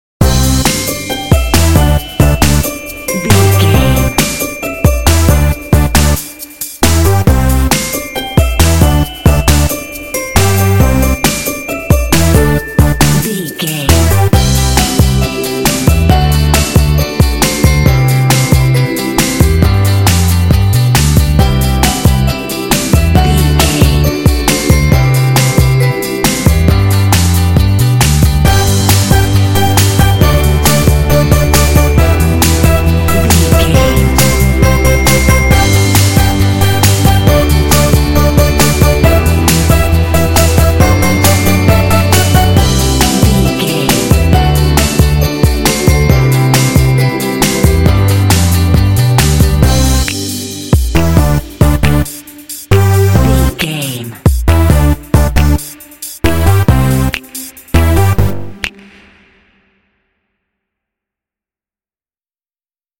Uplifting
Ionian/Major
energetic
playful
cheerful/happy
synthesiser
drums
piano
bass guitar
acoustic guitar
contemporary underscore